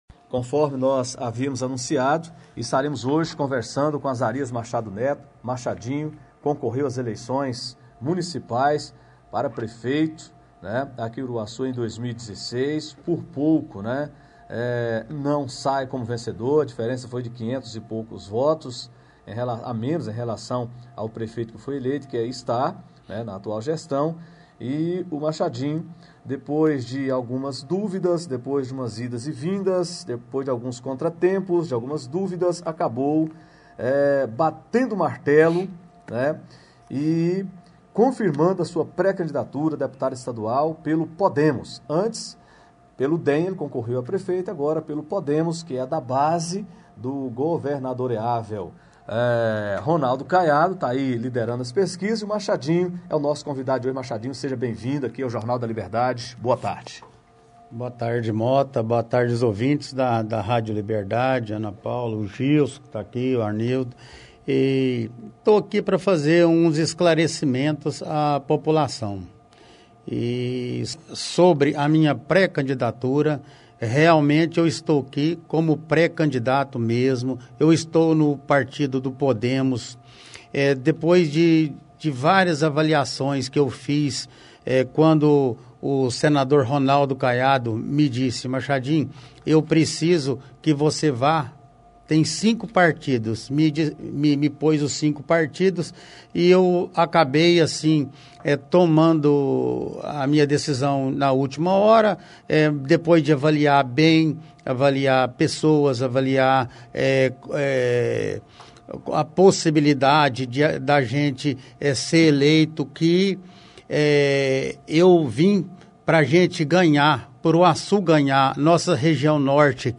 encerra série de entrevistas na rádio Liberdade AM de Uruaçu